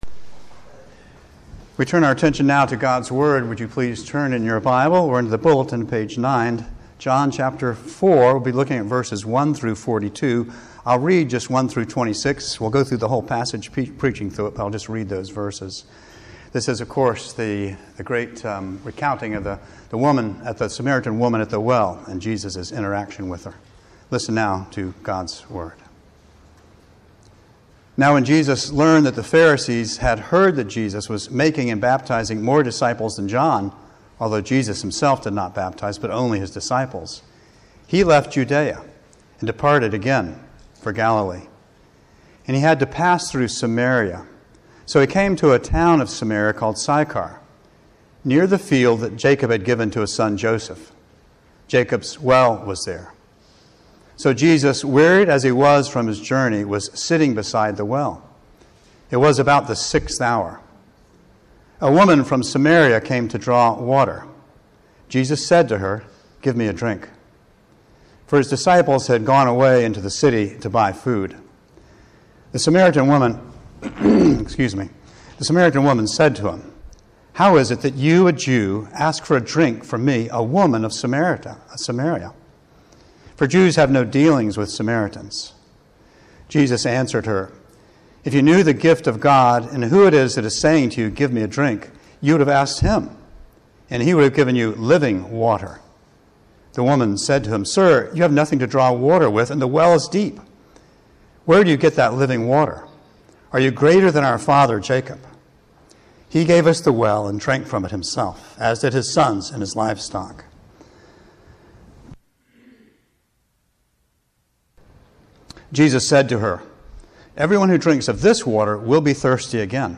Sermons | Christ Presbyterian Church